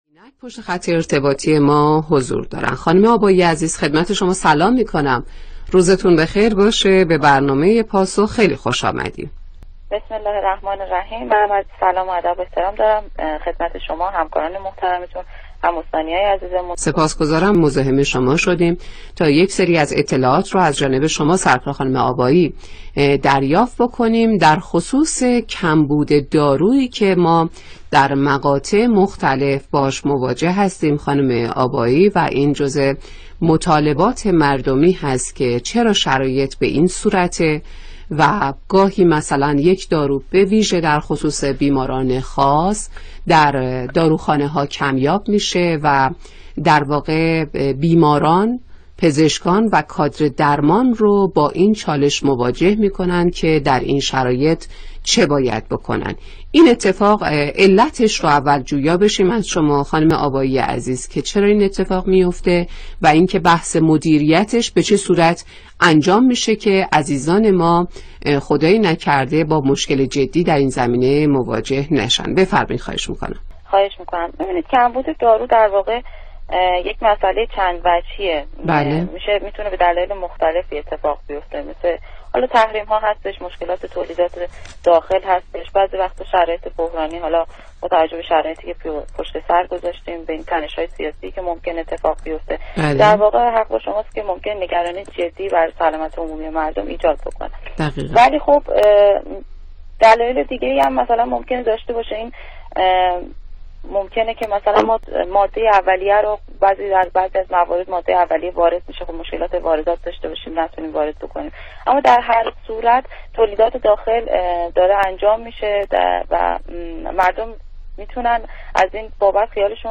برنامه رادیویی